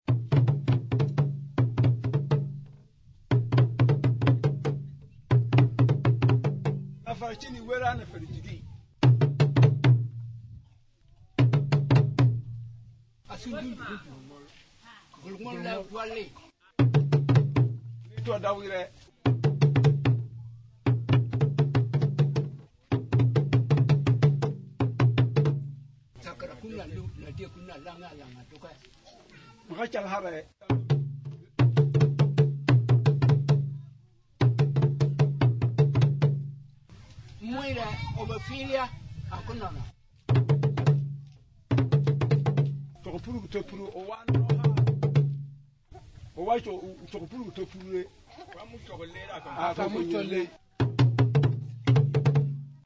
The tengpenning is a single-skin speaking drum used by the Sissala and was taken over from the Akan (Ashanti), where it is called atumpan.
The tengpenning drum is always played in pairs.
The tengpenning is usually played with two sticks, sometimes also with one stick and the fingertips.
Since Akan (Twi) is a tonal language, it is used to transmit messages; the talking drum has two tones, a high and a low tone.